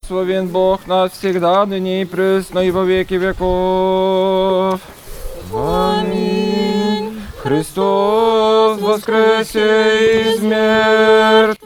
Poświęcenie serbskich grobów na Międzynarodowym Cmentarzu Jeńców Wojennych rozpoczęło drugi dzień stargardzkich uroczystości związanych z 73. rocznicą zakończenia II wojny światowej.
serbskie modły.mp3